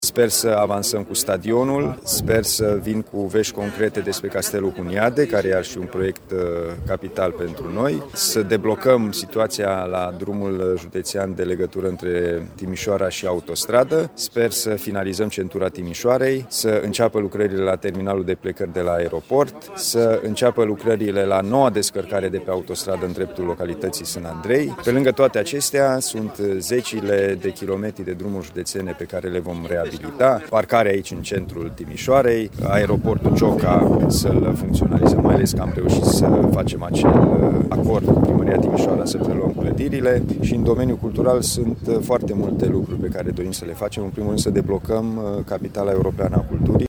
Administrația județeană are pe masă, în 2022, și alte proiecte de infrastructură, explică președintele Consiliului Județean Timiș, Alin Nica.